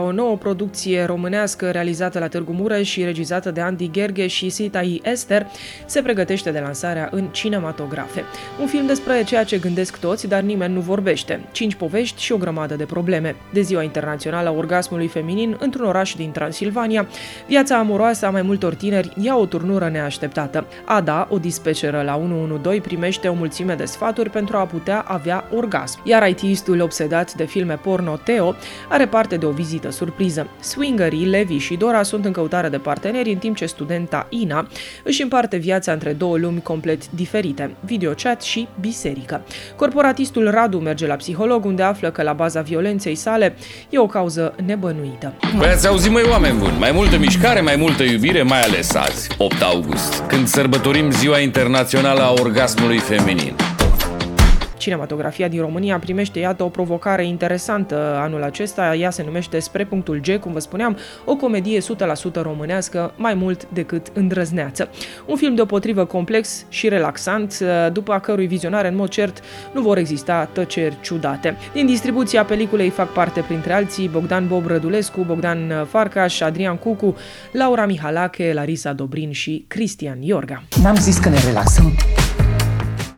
21-sept-Cinemuzica-Promo-Spre-punctul-G.mp3